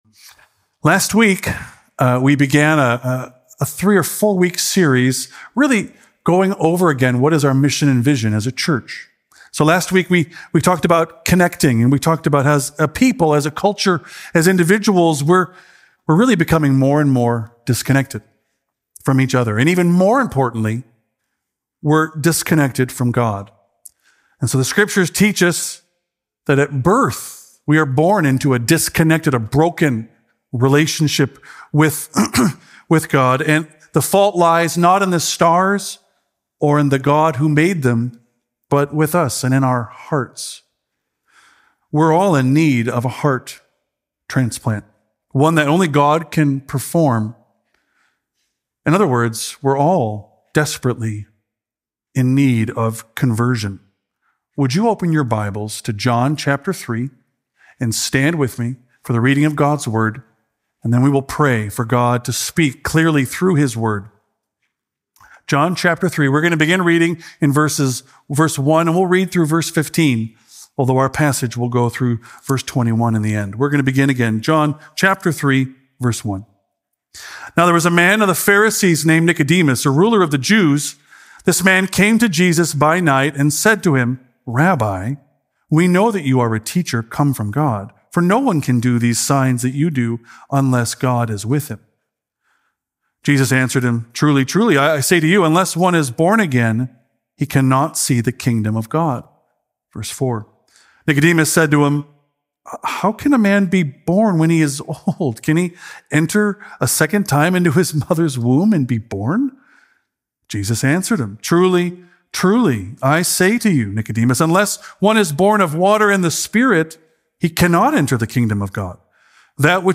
A message from the series "1 Peter." Christ-centered hope leads to holy living. Christians should set their hope fully on the grace of Jesus Christ, and that this hope should lead to holy living, which includes imitating God and having a holy fear of Him.